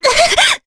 Veronica-Vox_Damage_jp_02.wav